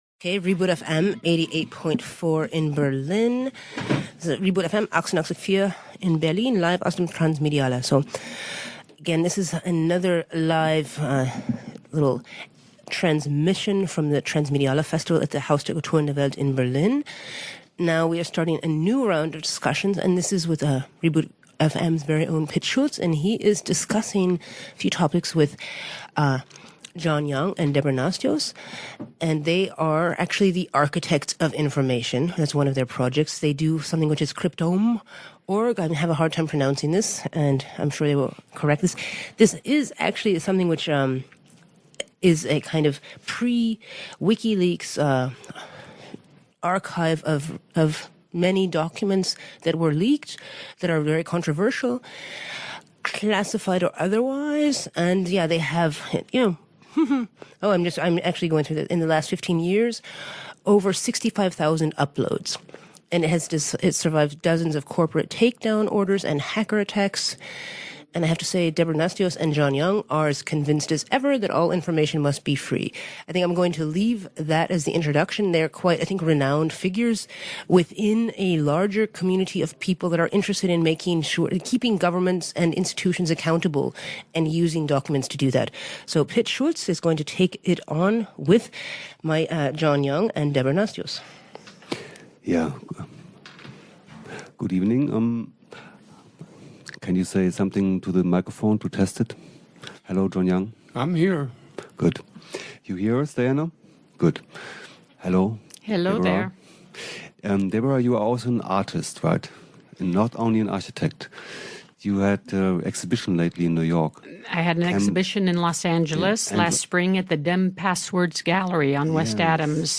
Cryptome Interview
Transmediale Berlin